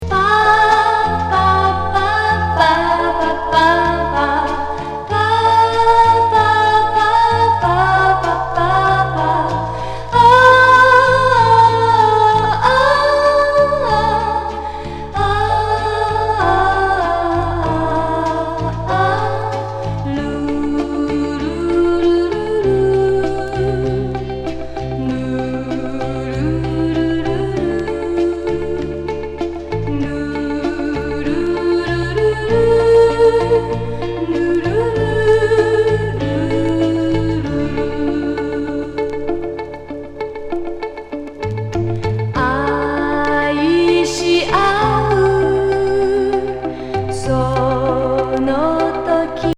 ド・サイケ・スキャット!!